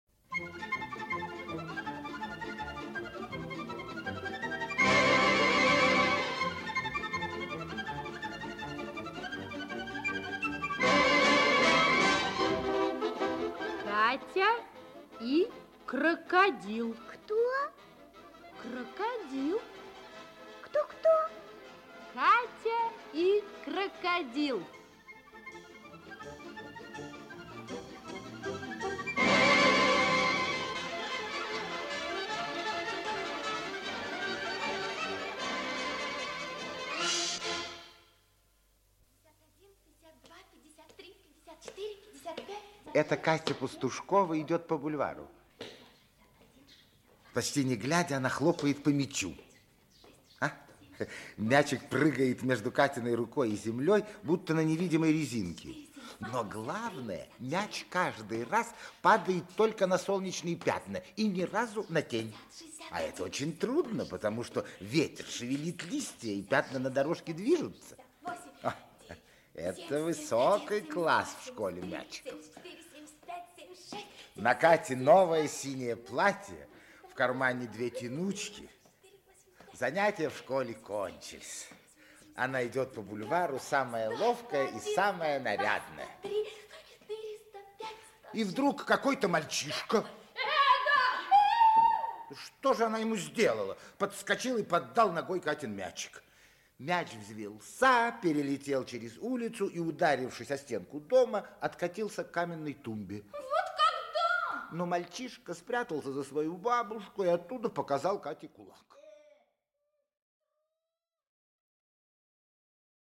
Часть 1 Автор Григорий Ягдфельд Читает аудиокнигу Актерский коллектив.